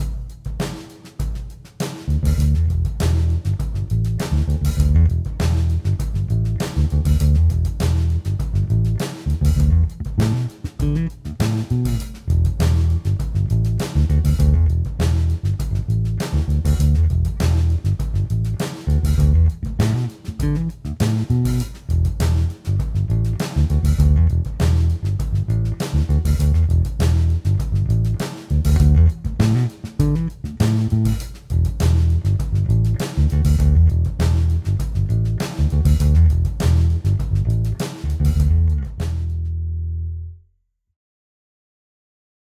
Edit: nahráváno přes VSTčko Ampeg B15R
1. Mim Precl, ale asi nejlepší, co jsem měl kdy v rukou, úplně nové struny. 2.